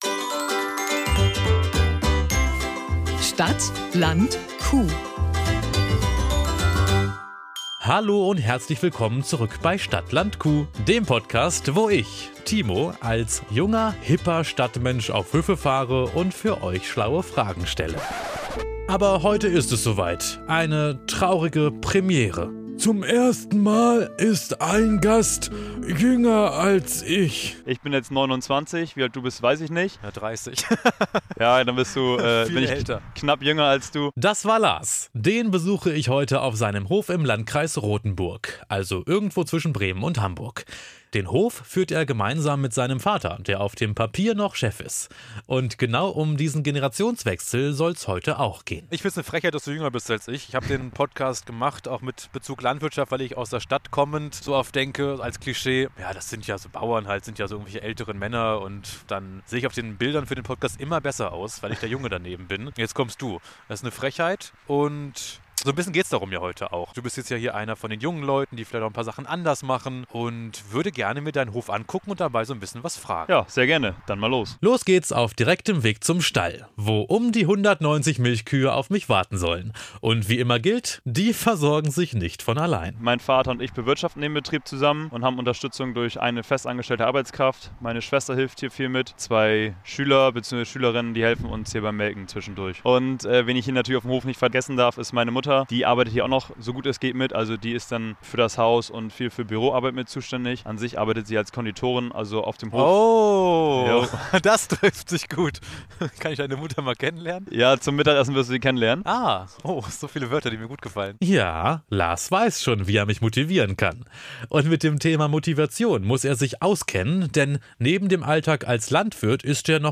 Zwischen Futterschieber-Duellen und Kuhzähl-Versuchen sprechen die beiden über Generationsunterschiede auf dem Hof: Wie verändert neue Technik die Arbeit?